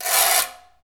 PRC GRATER 1.wav